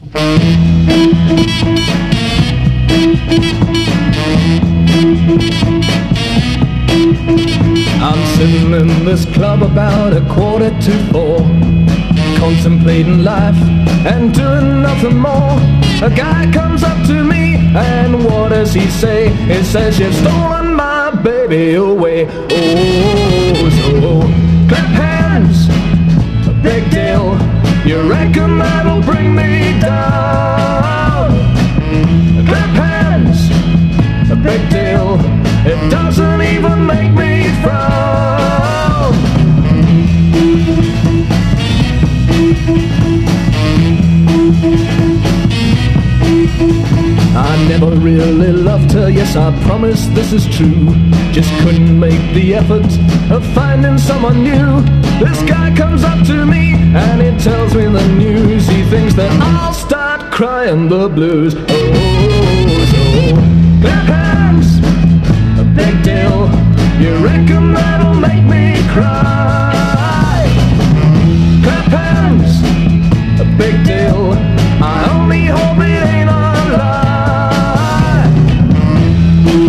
EASY LISTENING / VOCAL / CHORUS / SOFT ROCK